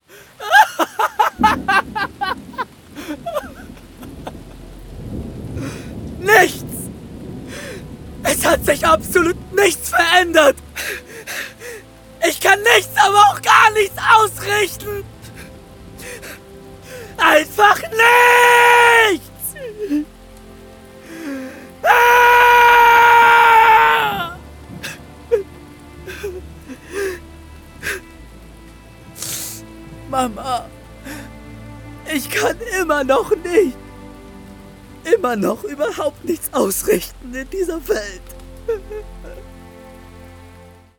Emotional nach tödlichem Scheitern mit gebrochener Stimme.
Gebrochener Glaube Hörspiel Schmerzerfüllt Emotional nach tödlichem Scheitern mit gebrochener Stimme. https